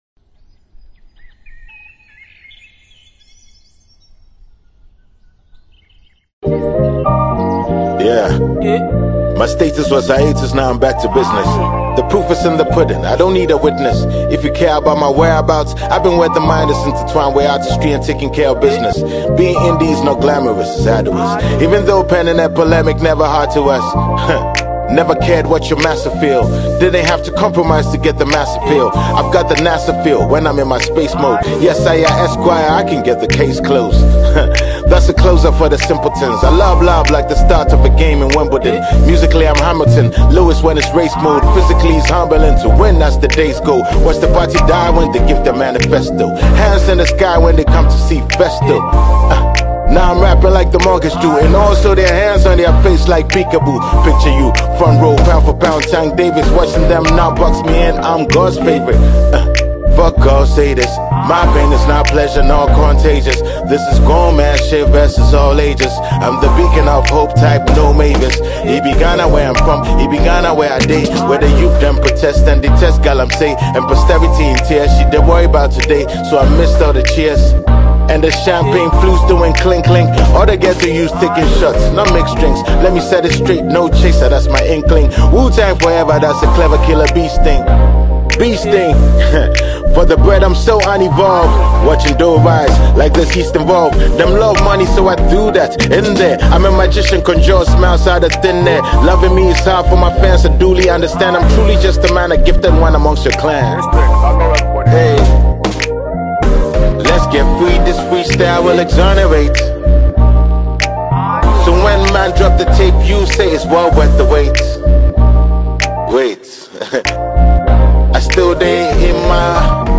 Ghanaian rapper, songwriter and award winning musician
captivating freestyle